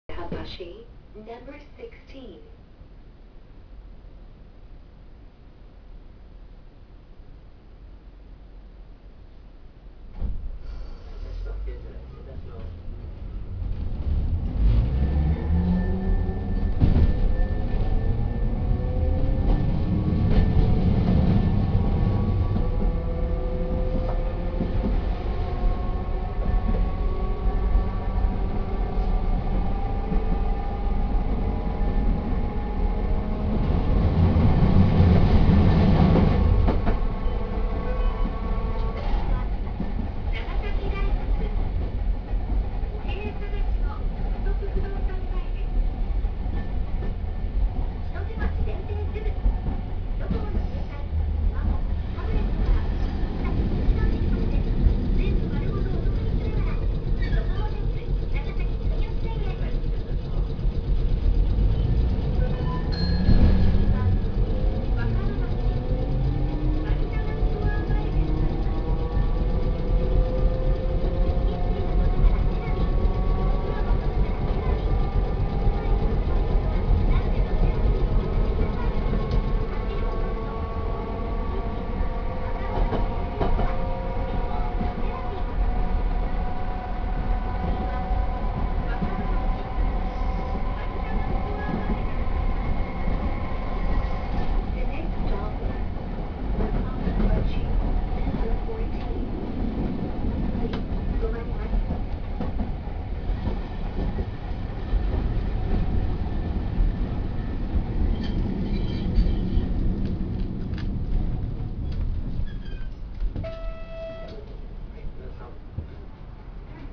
・300形走行音
【本線】岩屋橋(の直前)〜若葉町（1分56秒：636KB）
他の200系統と何も変わりません。